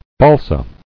[bal·sa]